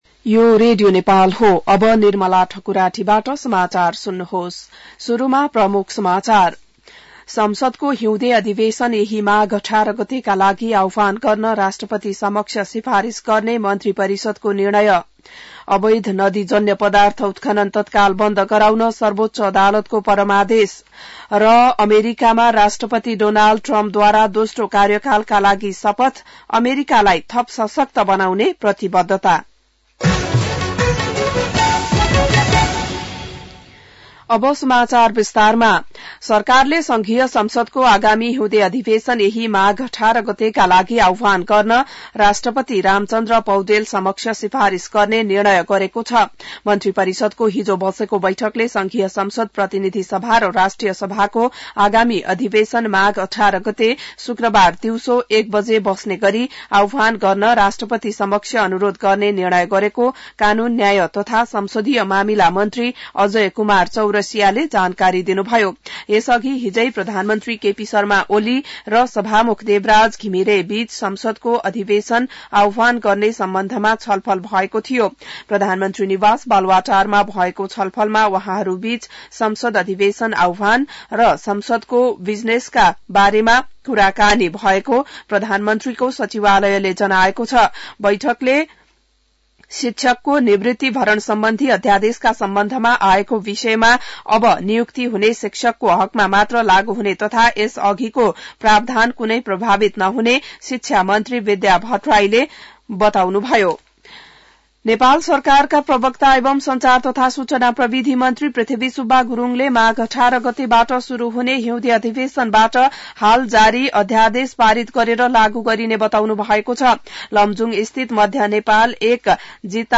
बिहान ९ बजेको नेपाली समाचार : ९ माघ , २०८१